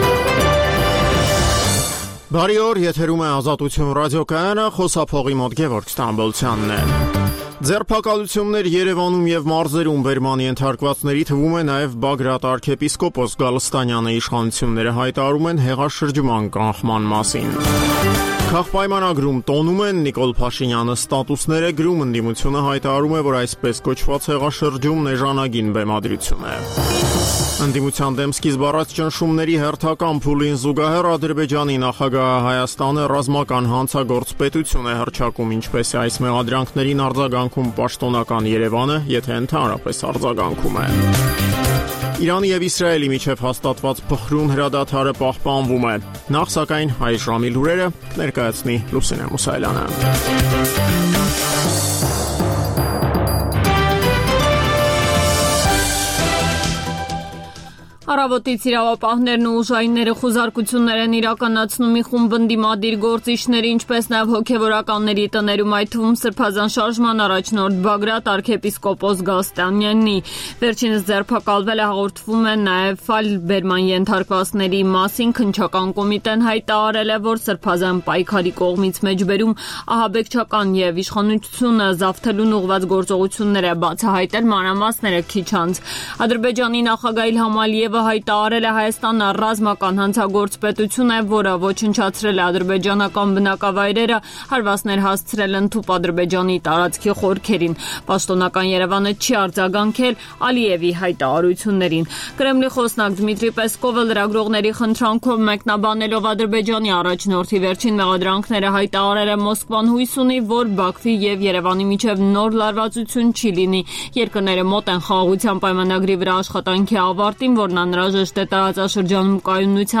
Տեղական եւ միջազգային լուրեր, ռեպորտաժներ զարգացող իրադարձությունների մասին, այդ թվում՝ ուղիղ եթերում, հարցազրույցներ, տեղական եւ միջազգային մամուլի տեսություն: